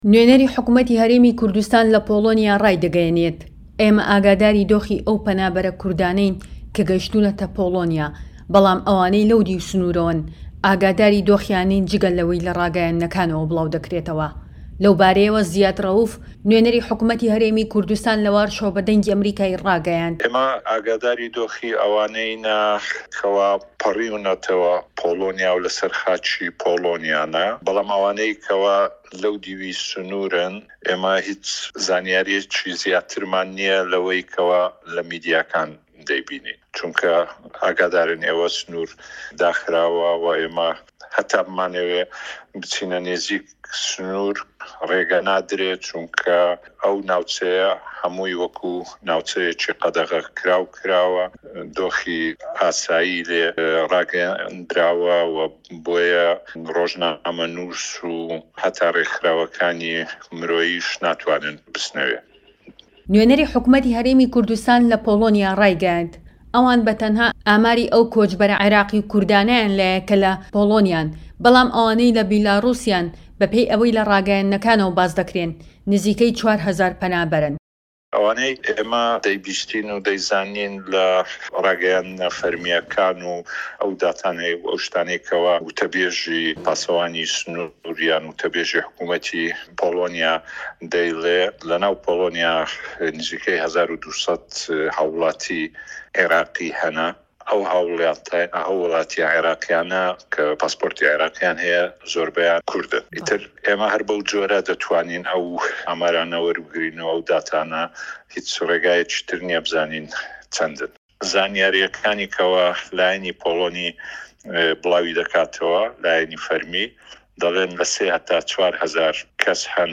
هه‌رێمه‌ کوردیـیه‌کان - گفتوگۆکان
دەقی ڕاپۆرتێک و وتەکانی زیاد ڕەئوف -Nov 10-021